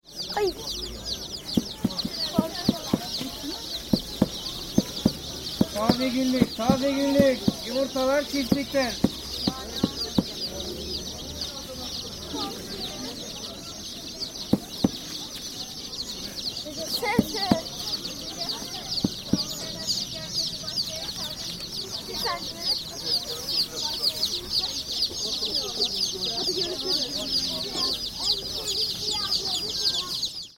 Bazaar chicks
Friday, midday at Çengelköy
People are walking up and down the street. Two young girls have stopped to watch into the two card board boxes, with chicks cheeping inside.
Hear the chicks. You’ll also hear the salesman shouting ‘Taze günlük, taze günlük yumurtalar çiftlikten!’ (Fresh daily, fresh daily eggs from the farm!)